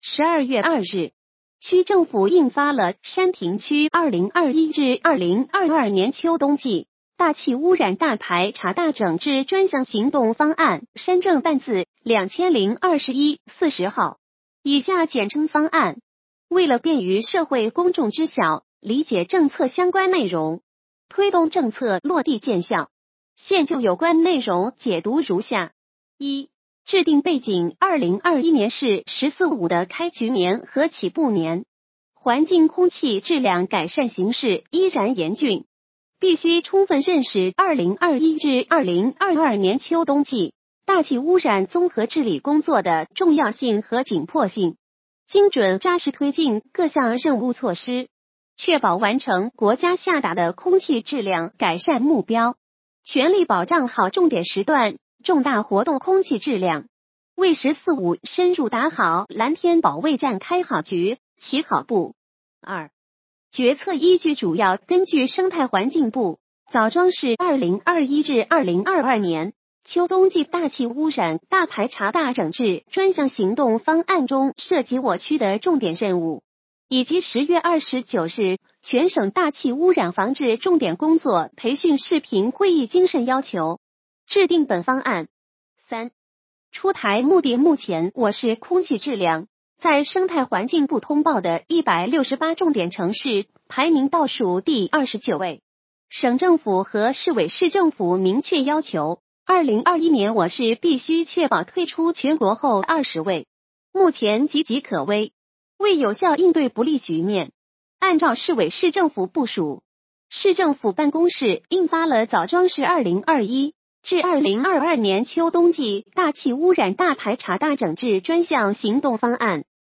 语音解读